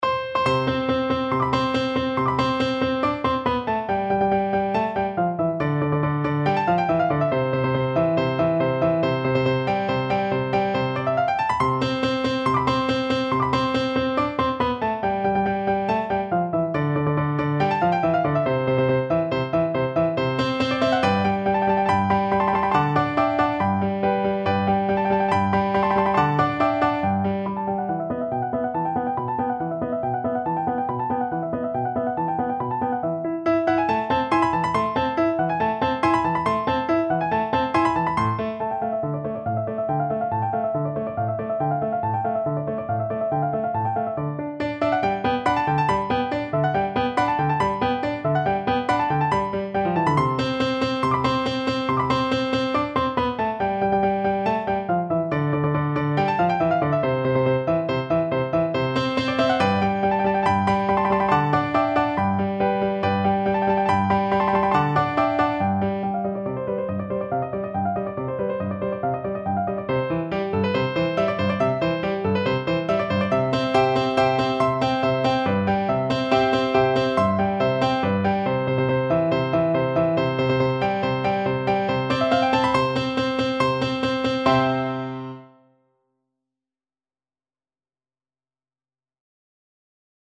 ~ = 140 Allegro vivace (View more music marked Allegro)
Classical (View more Classical Viola Music)